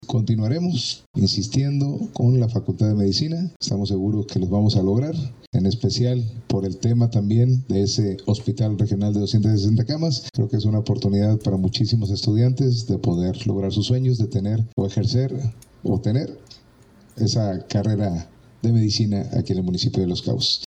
INSERT-ALCALDE-FACULTAD-DE-MEDICINA-LOS-CABOS.mp3